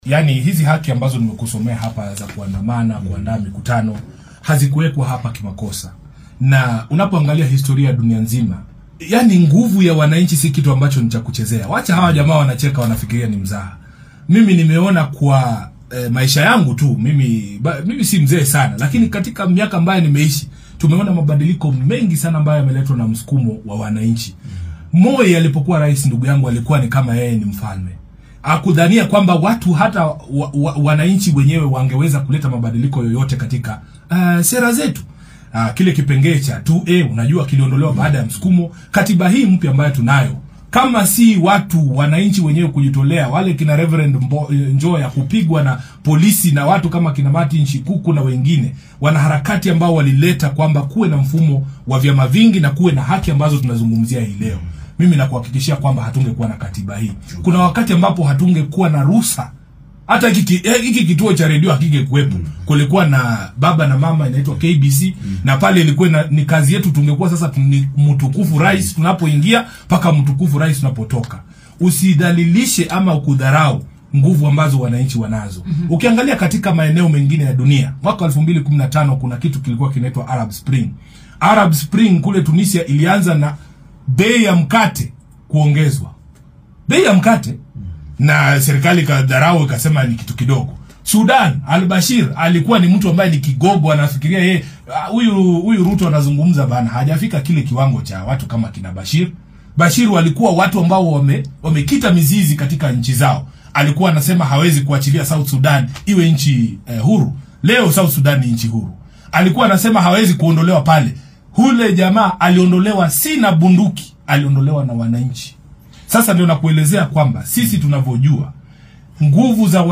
Edwin Sifuna oo wareysi gaar ah siinayay idaacadda Radio Citizen ayaa sheegay in mucaaradka ay hanjabaadooda ka dhabeyn doonaan haddii dowladdu aynan qaadin tallaabo degdeg ah.